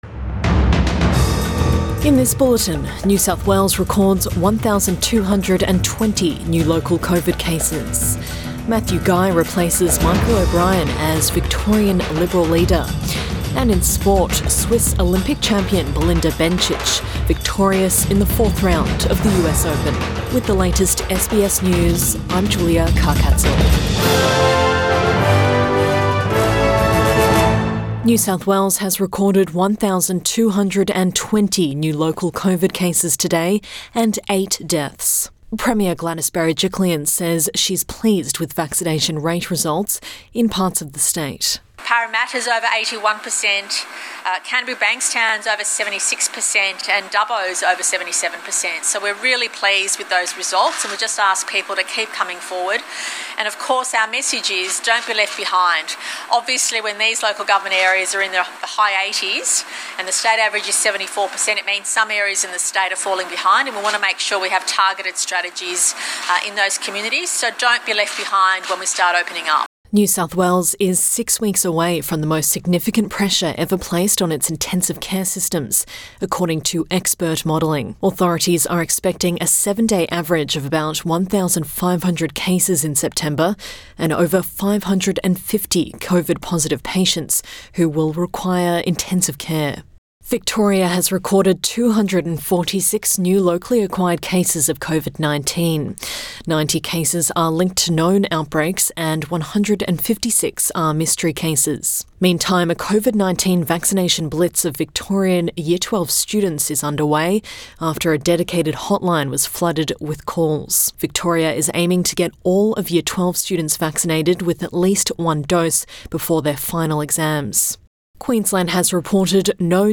Midday bulletin 7 September 2021